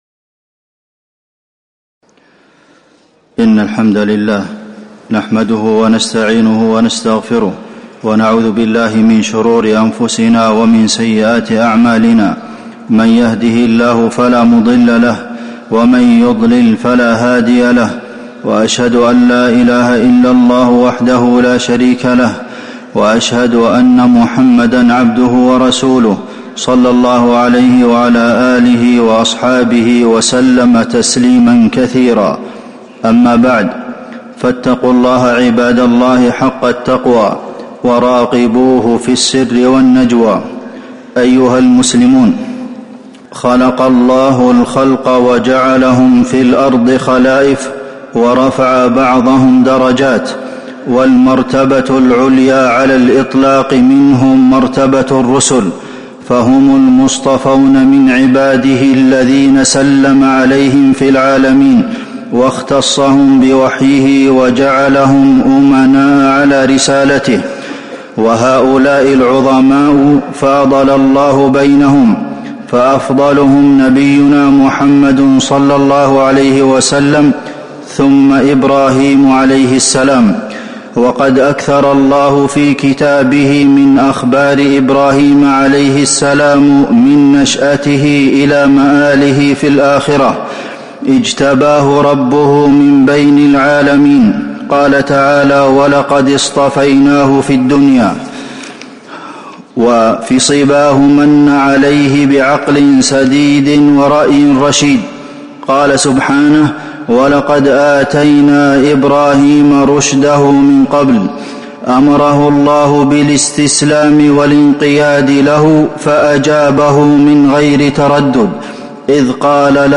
تاريخ النشر ٢ جمادى الآخرة ١٤٤٥ هـ المكان: المسجد النبوي الشيخ: فضيلة الشيخ د. عبدالمحسن بن محمد القاسم فضيلة الشيخ د. عبدالمحسن بن محمد القاسم إمام الموحدين إبراهيم عليه السلام